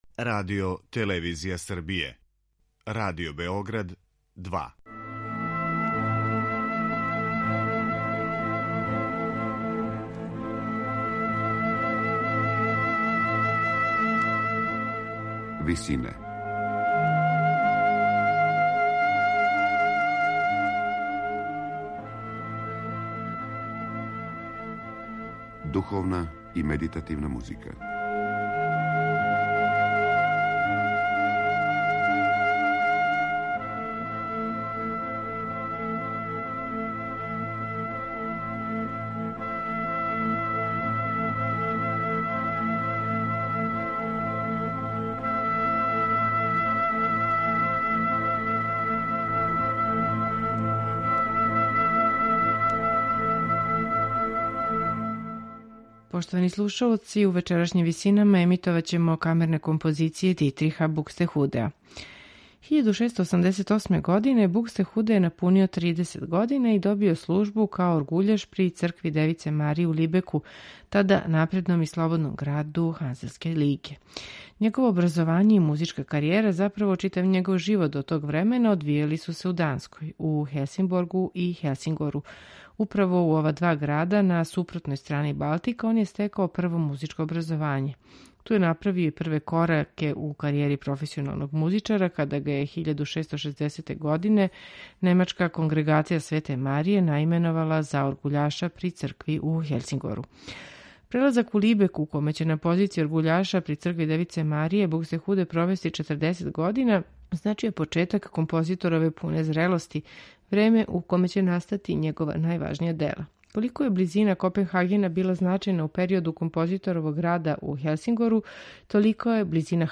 Камерна музика
Три сонате из ова два циклуса одабрали смо за вечерашње Висине . Слушаћете их у интерпретацији ансамбла Capriccio stravagante .